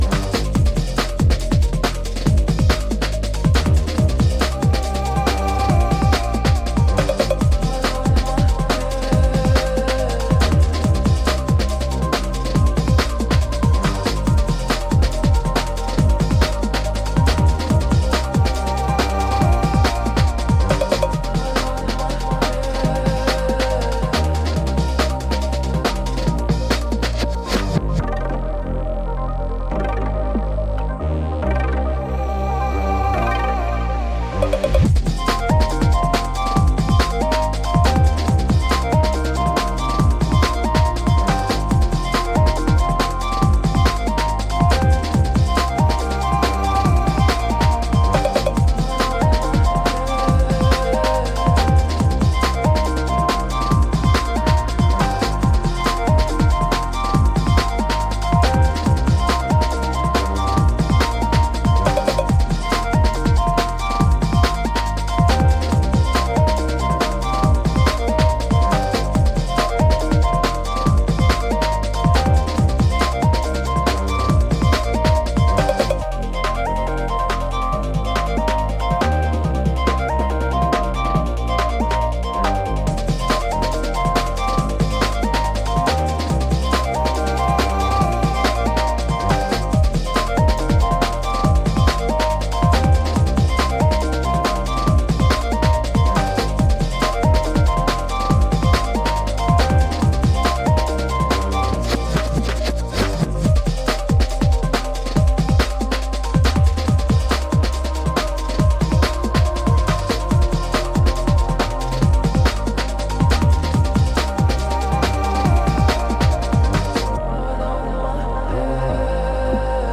UK Garage / Breaks